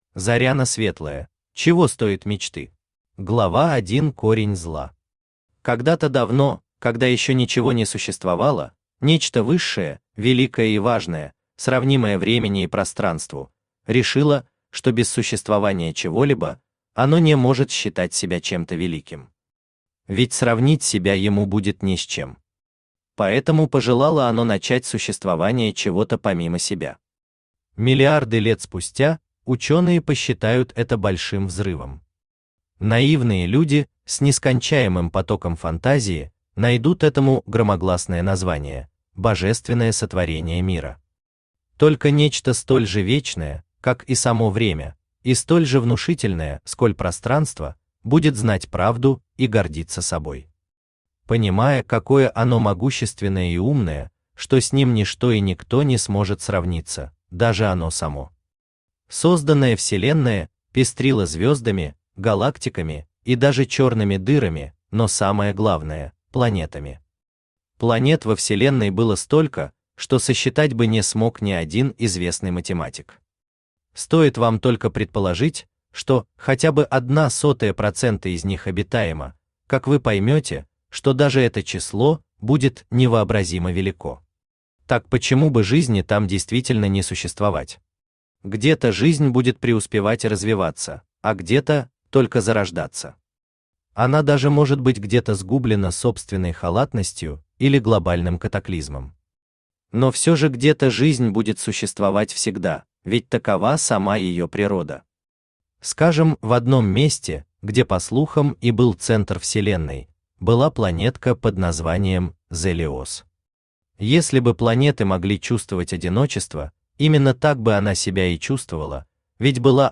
Аудиокнига Чего стоят мечты?
Автор Заряна Светлая Читает аудиокнигу Авточтец ЛитРес.